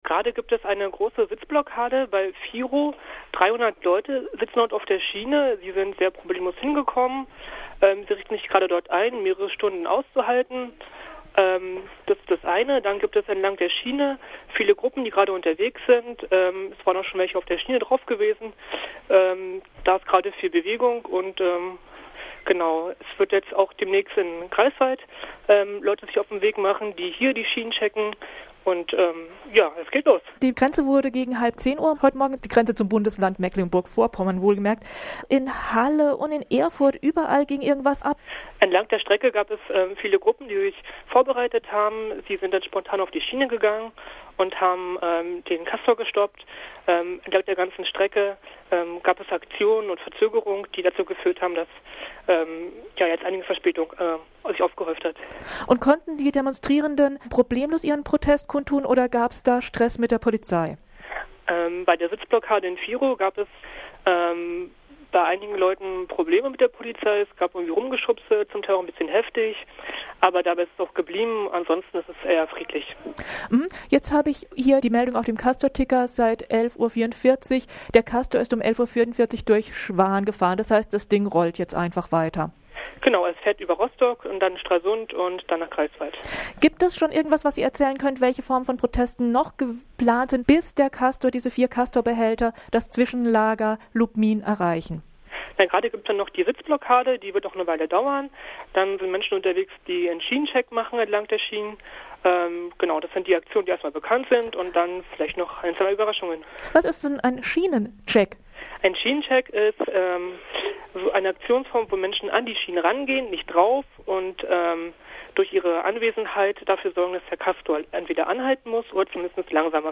Das Interview wurde am 16. Dezember um 12.05 geführt.